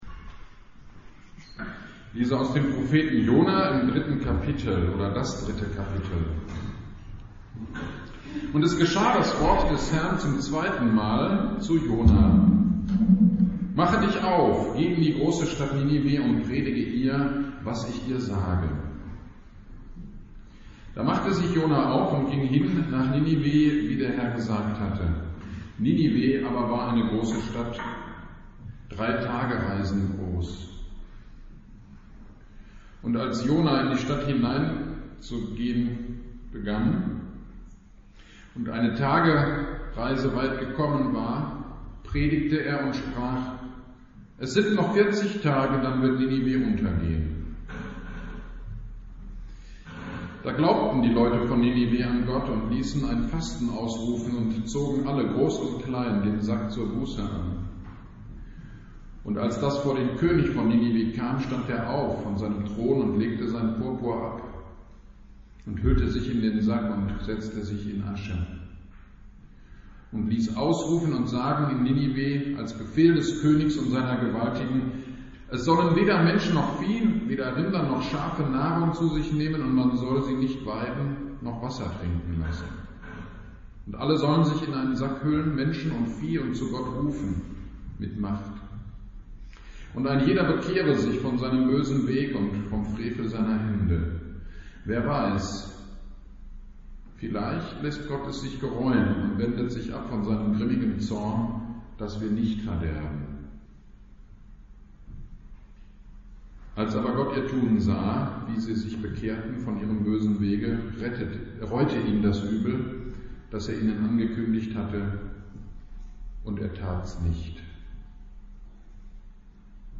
GD am 26.06.22 Predigt zu Jona 3
Predigt-zu-Jona-3.mp3